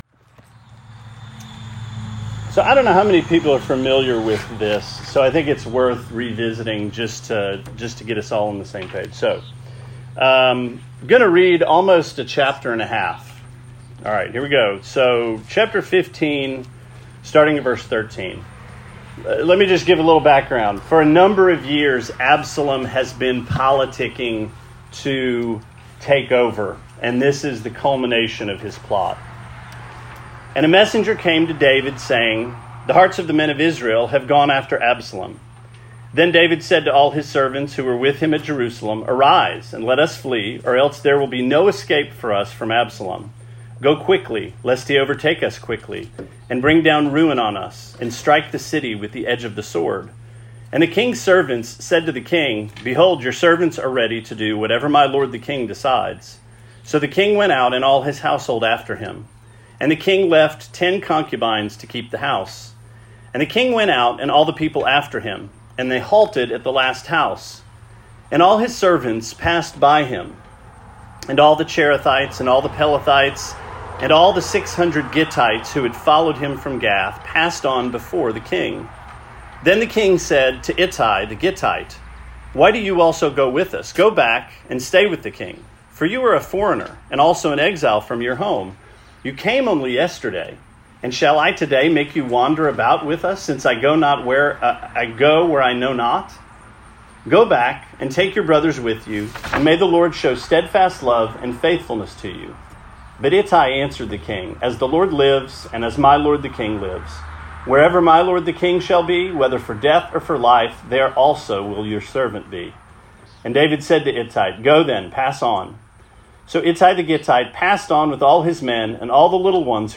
Sermon 7/17: If We'll Have It